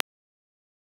Pixelated retro style for tech tips channel, high energy, no music. 0:10 Clean, responsive 8-bit click pulse. 0:01 , 8-bit, chiptune, (happy), male, visual novel voice effects 0:10
clean-responsive-8-bit-cl-epwcgxws.wav